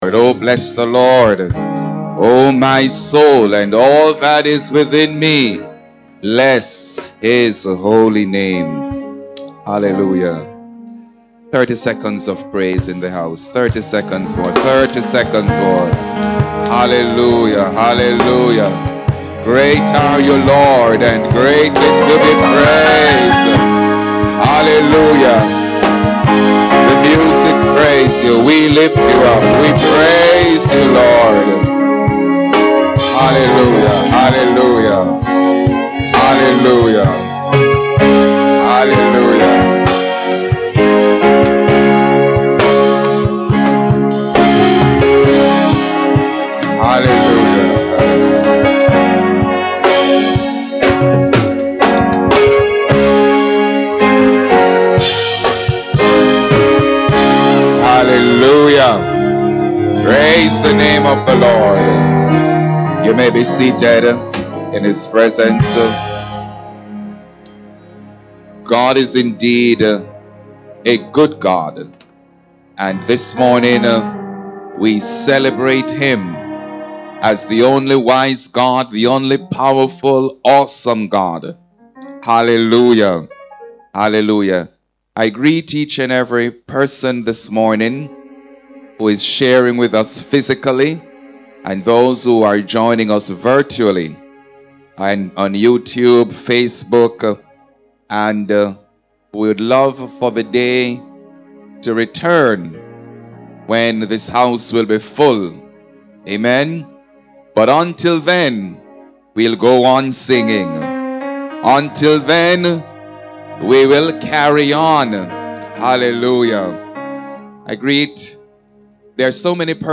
5Th Sunday Service – October 31, 2021 - Church of God of Prophecy, 25 Nugent Street